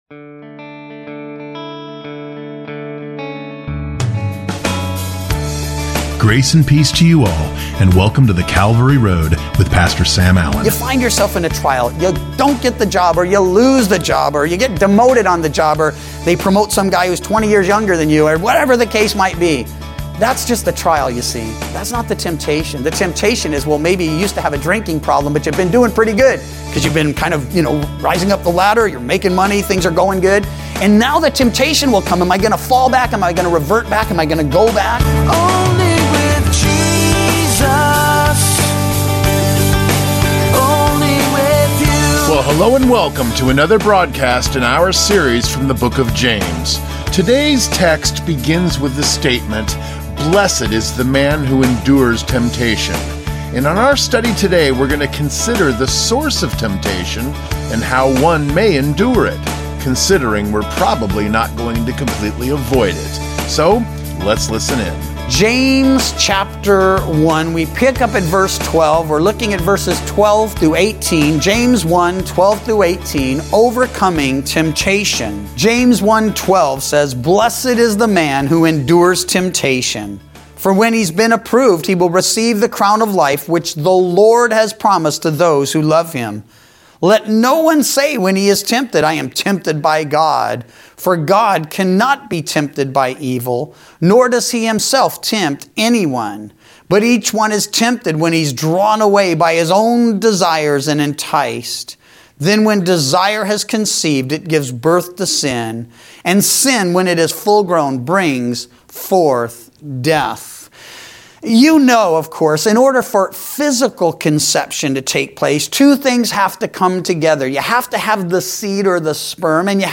Weekday Radio Program